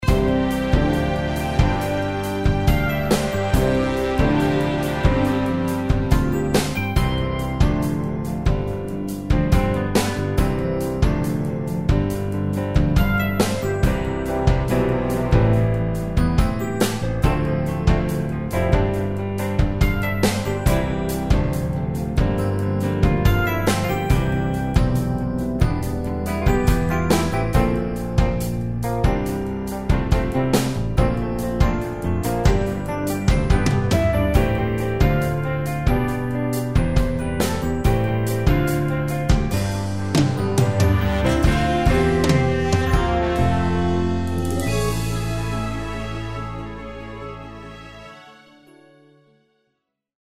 Musical
Instrumental , orchestral , backing track